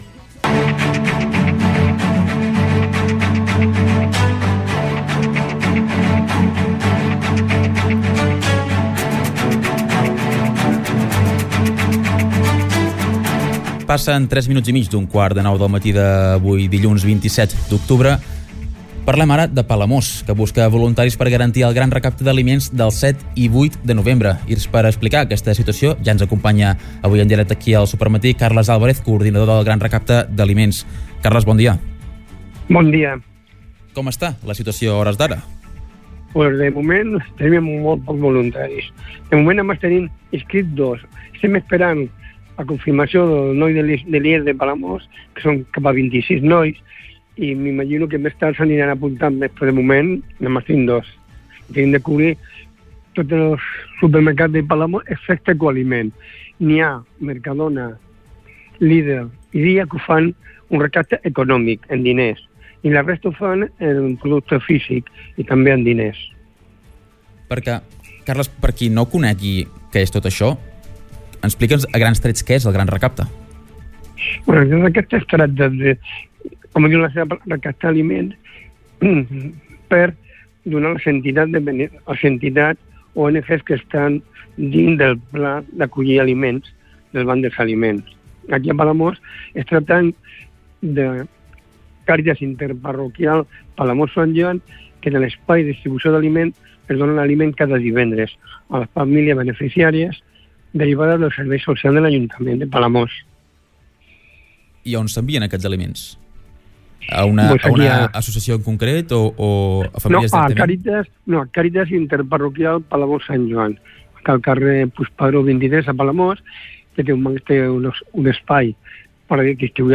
entrevista-supermati-dilluns.mp3